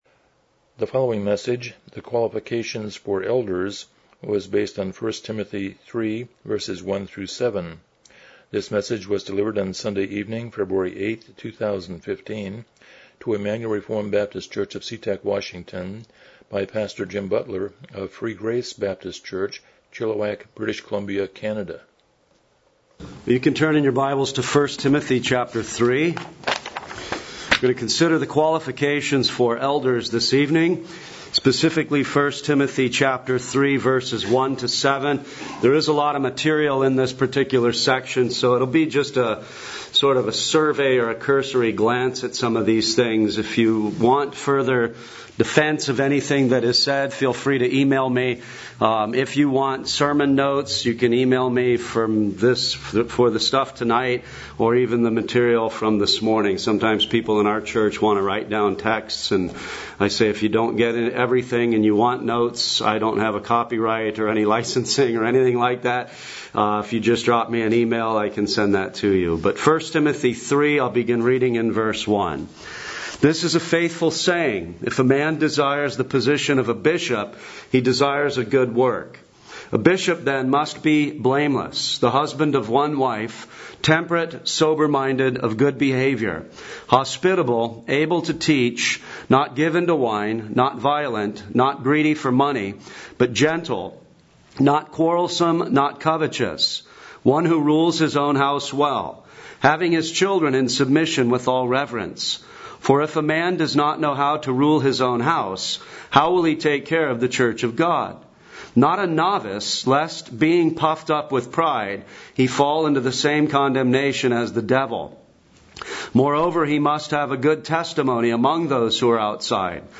Miscellaneous Passage: 1 Timothy 3:1-7 Service Type: Morning Worship « An Overview of Samson The Demand for a King